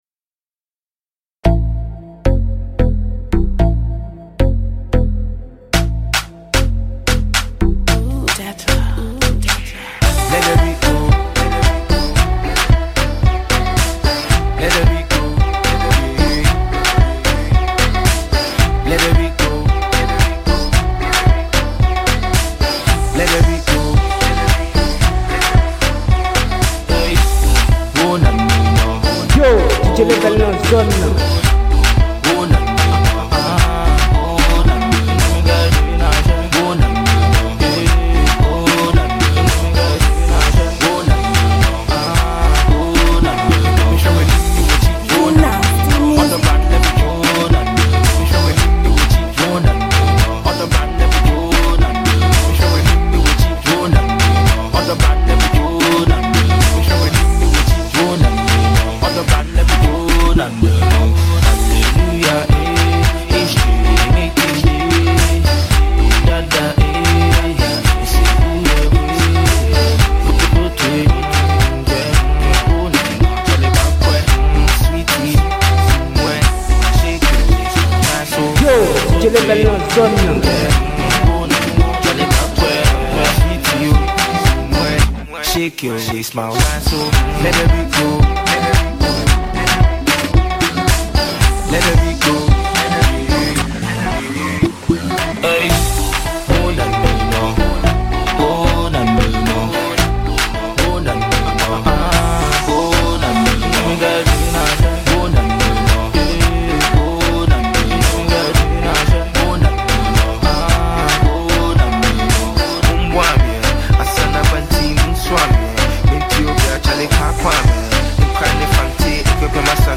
Genre: Mix.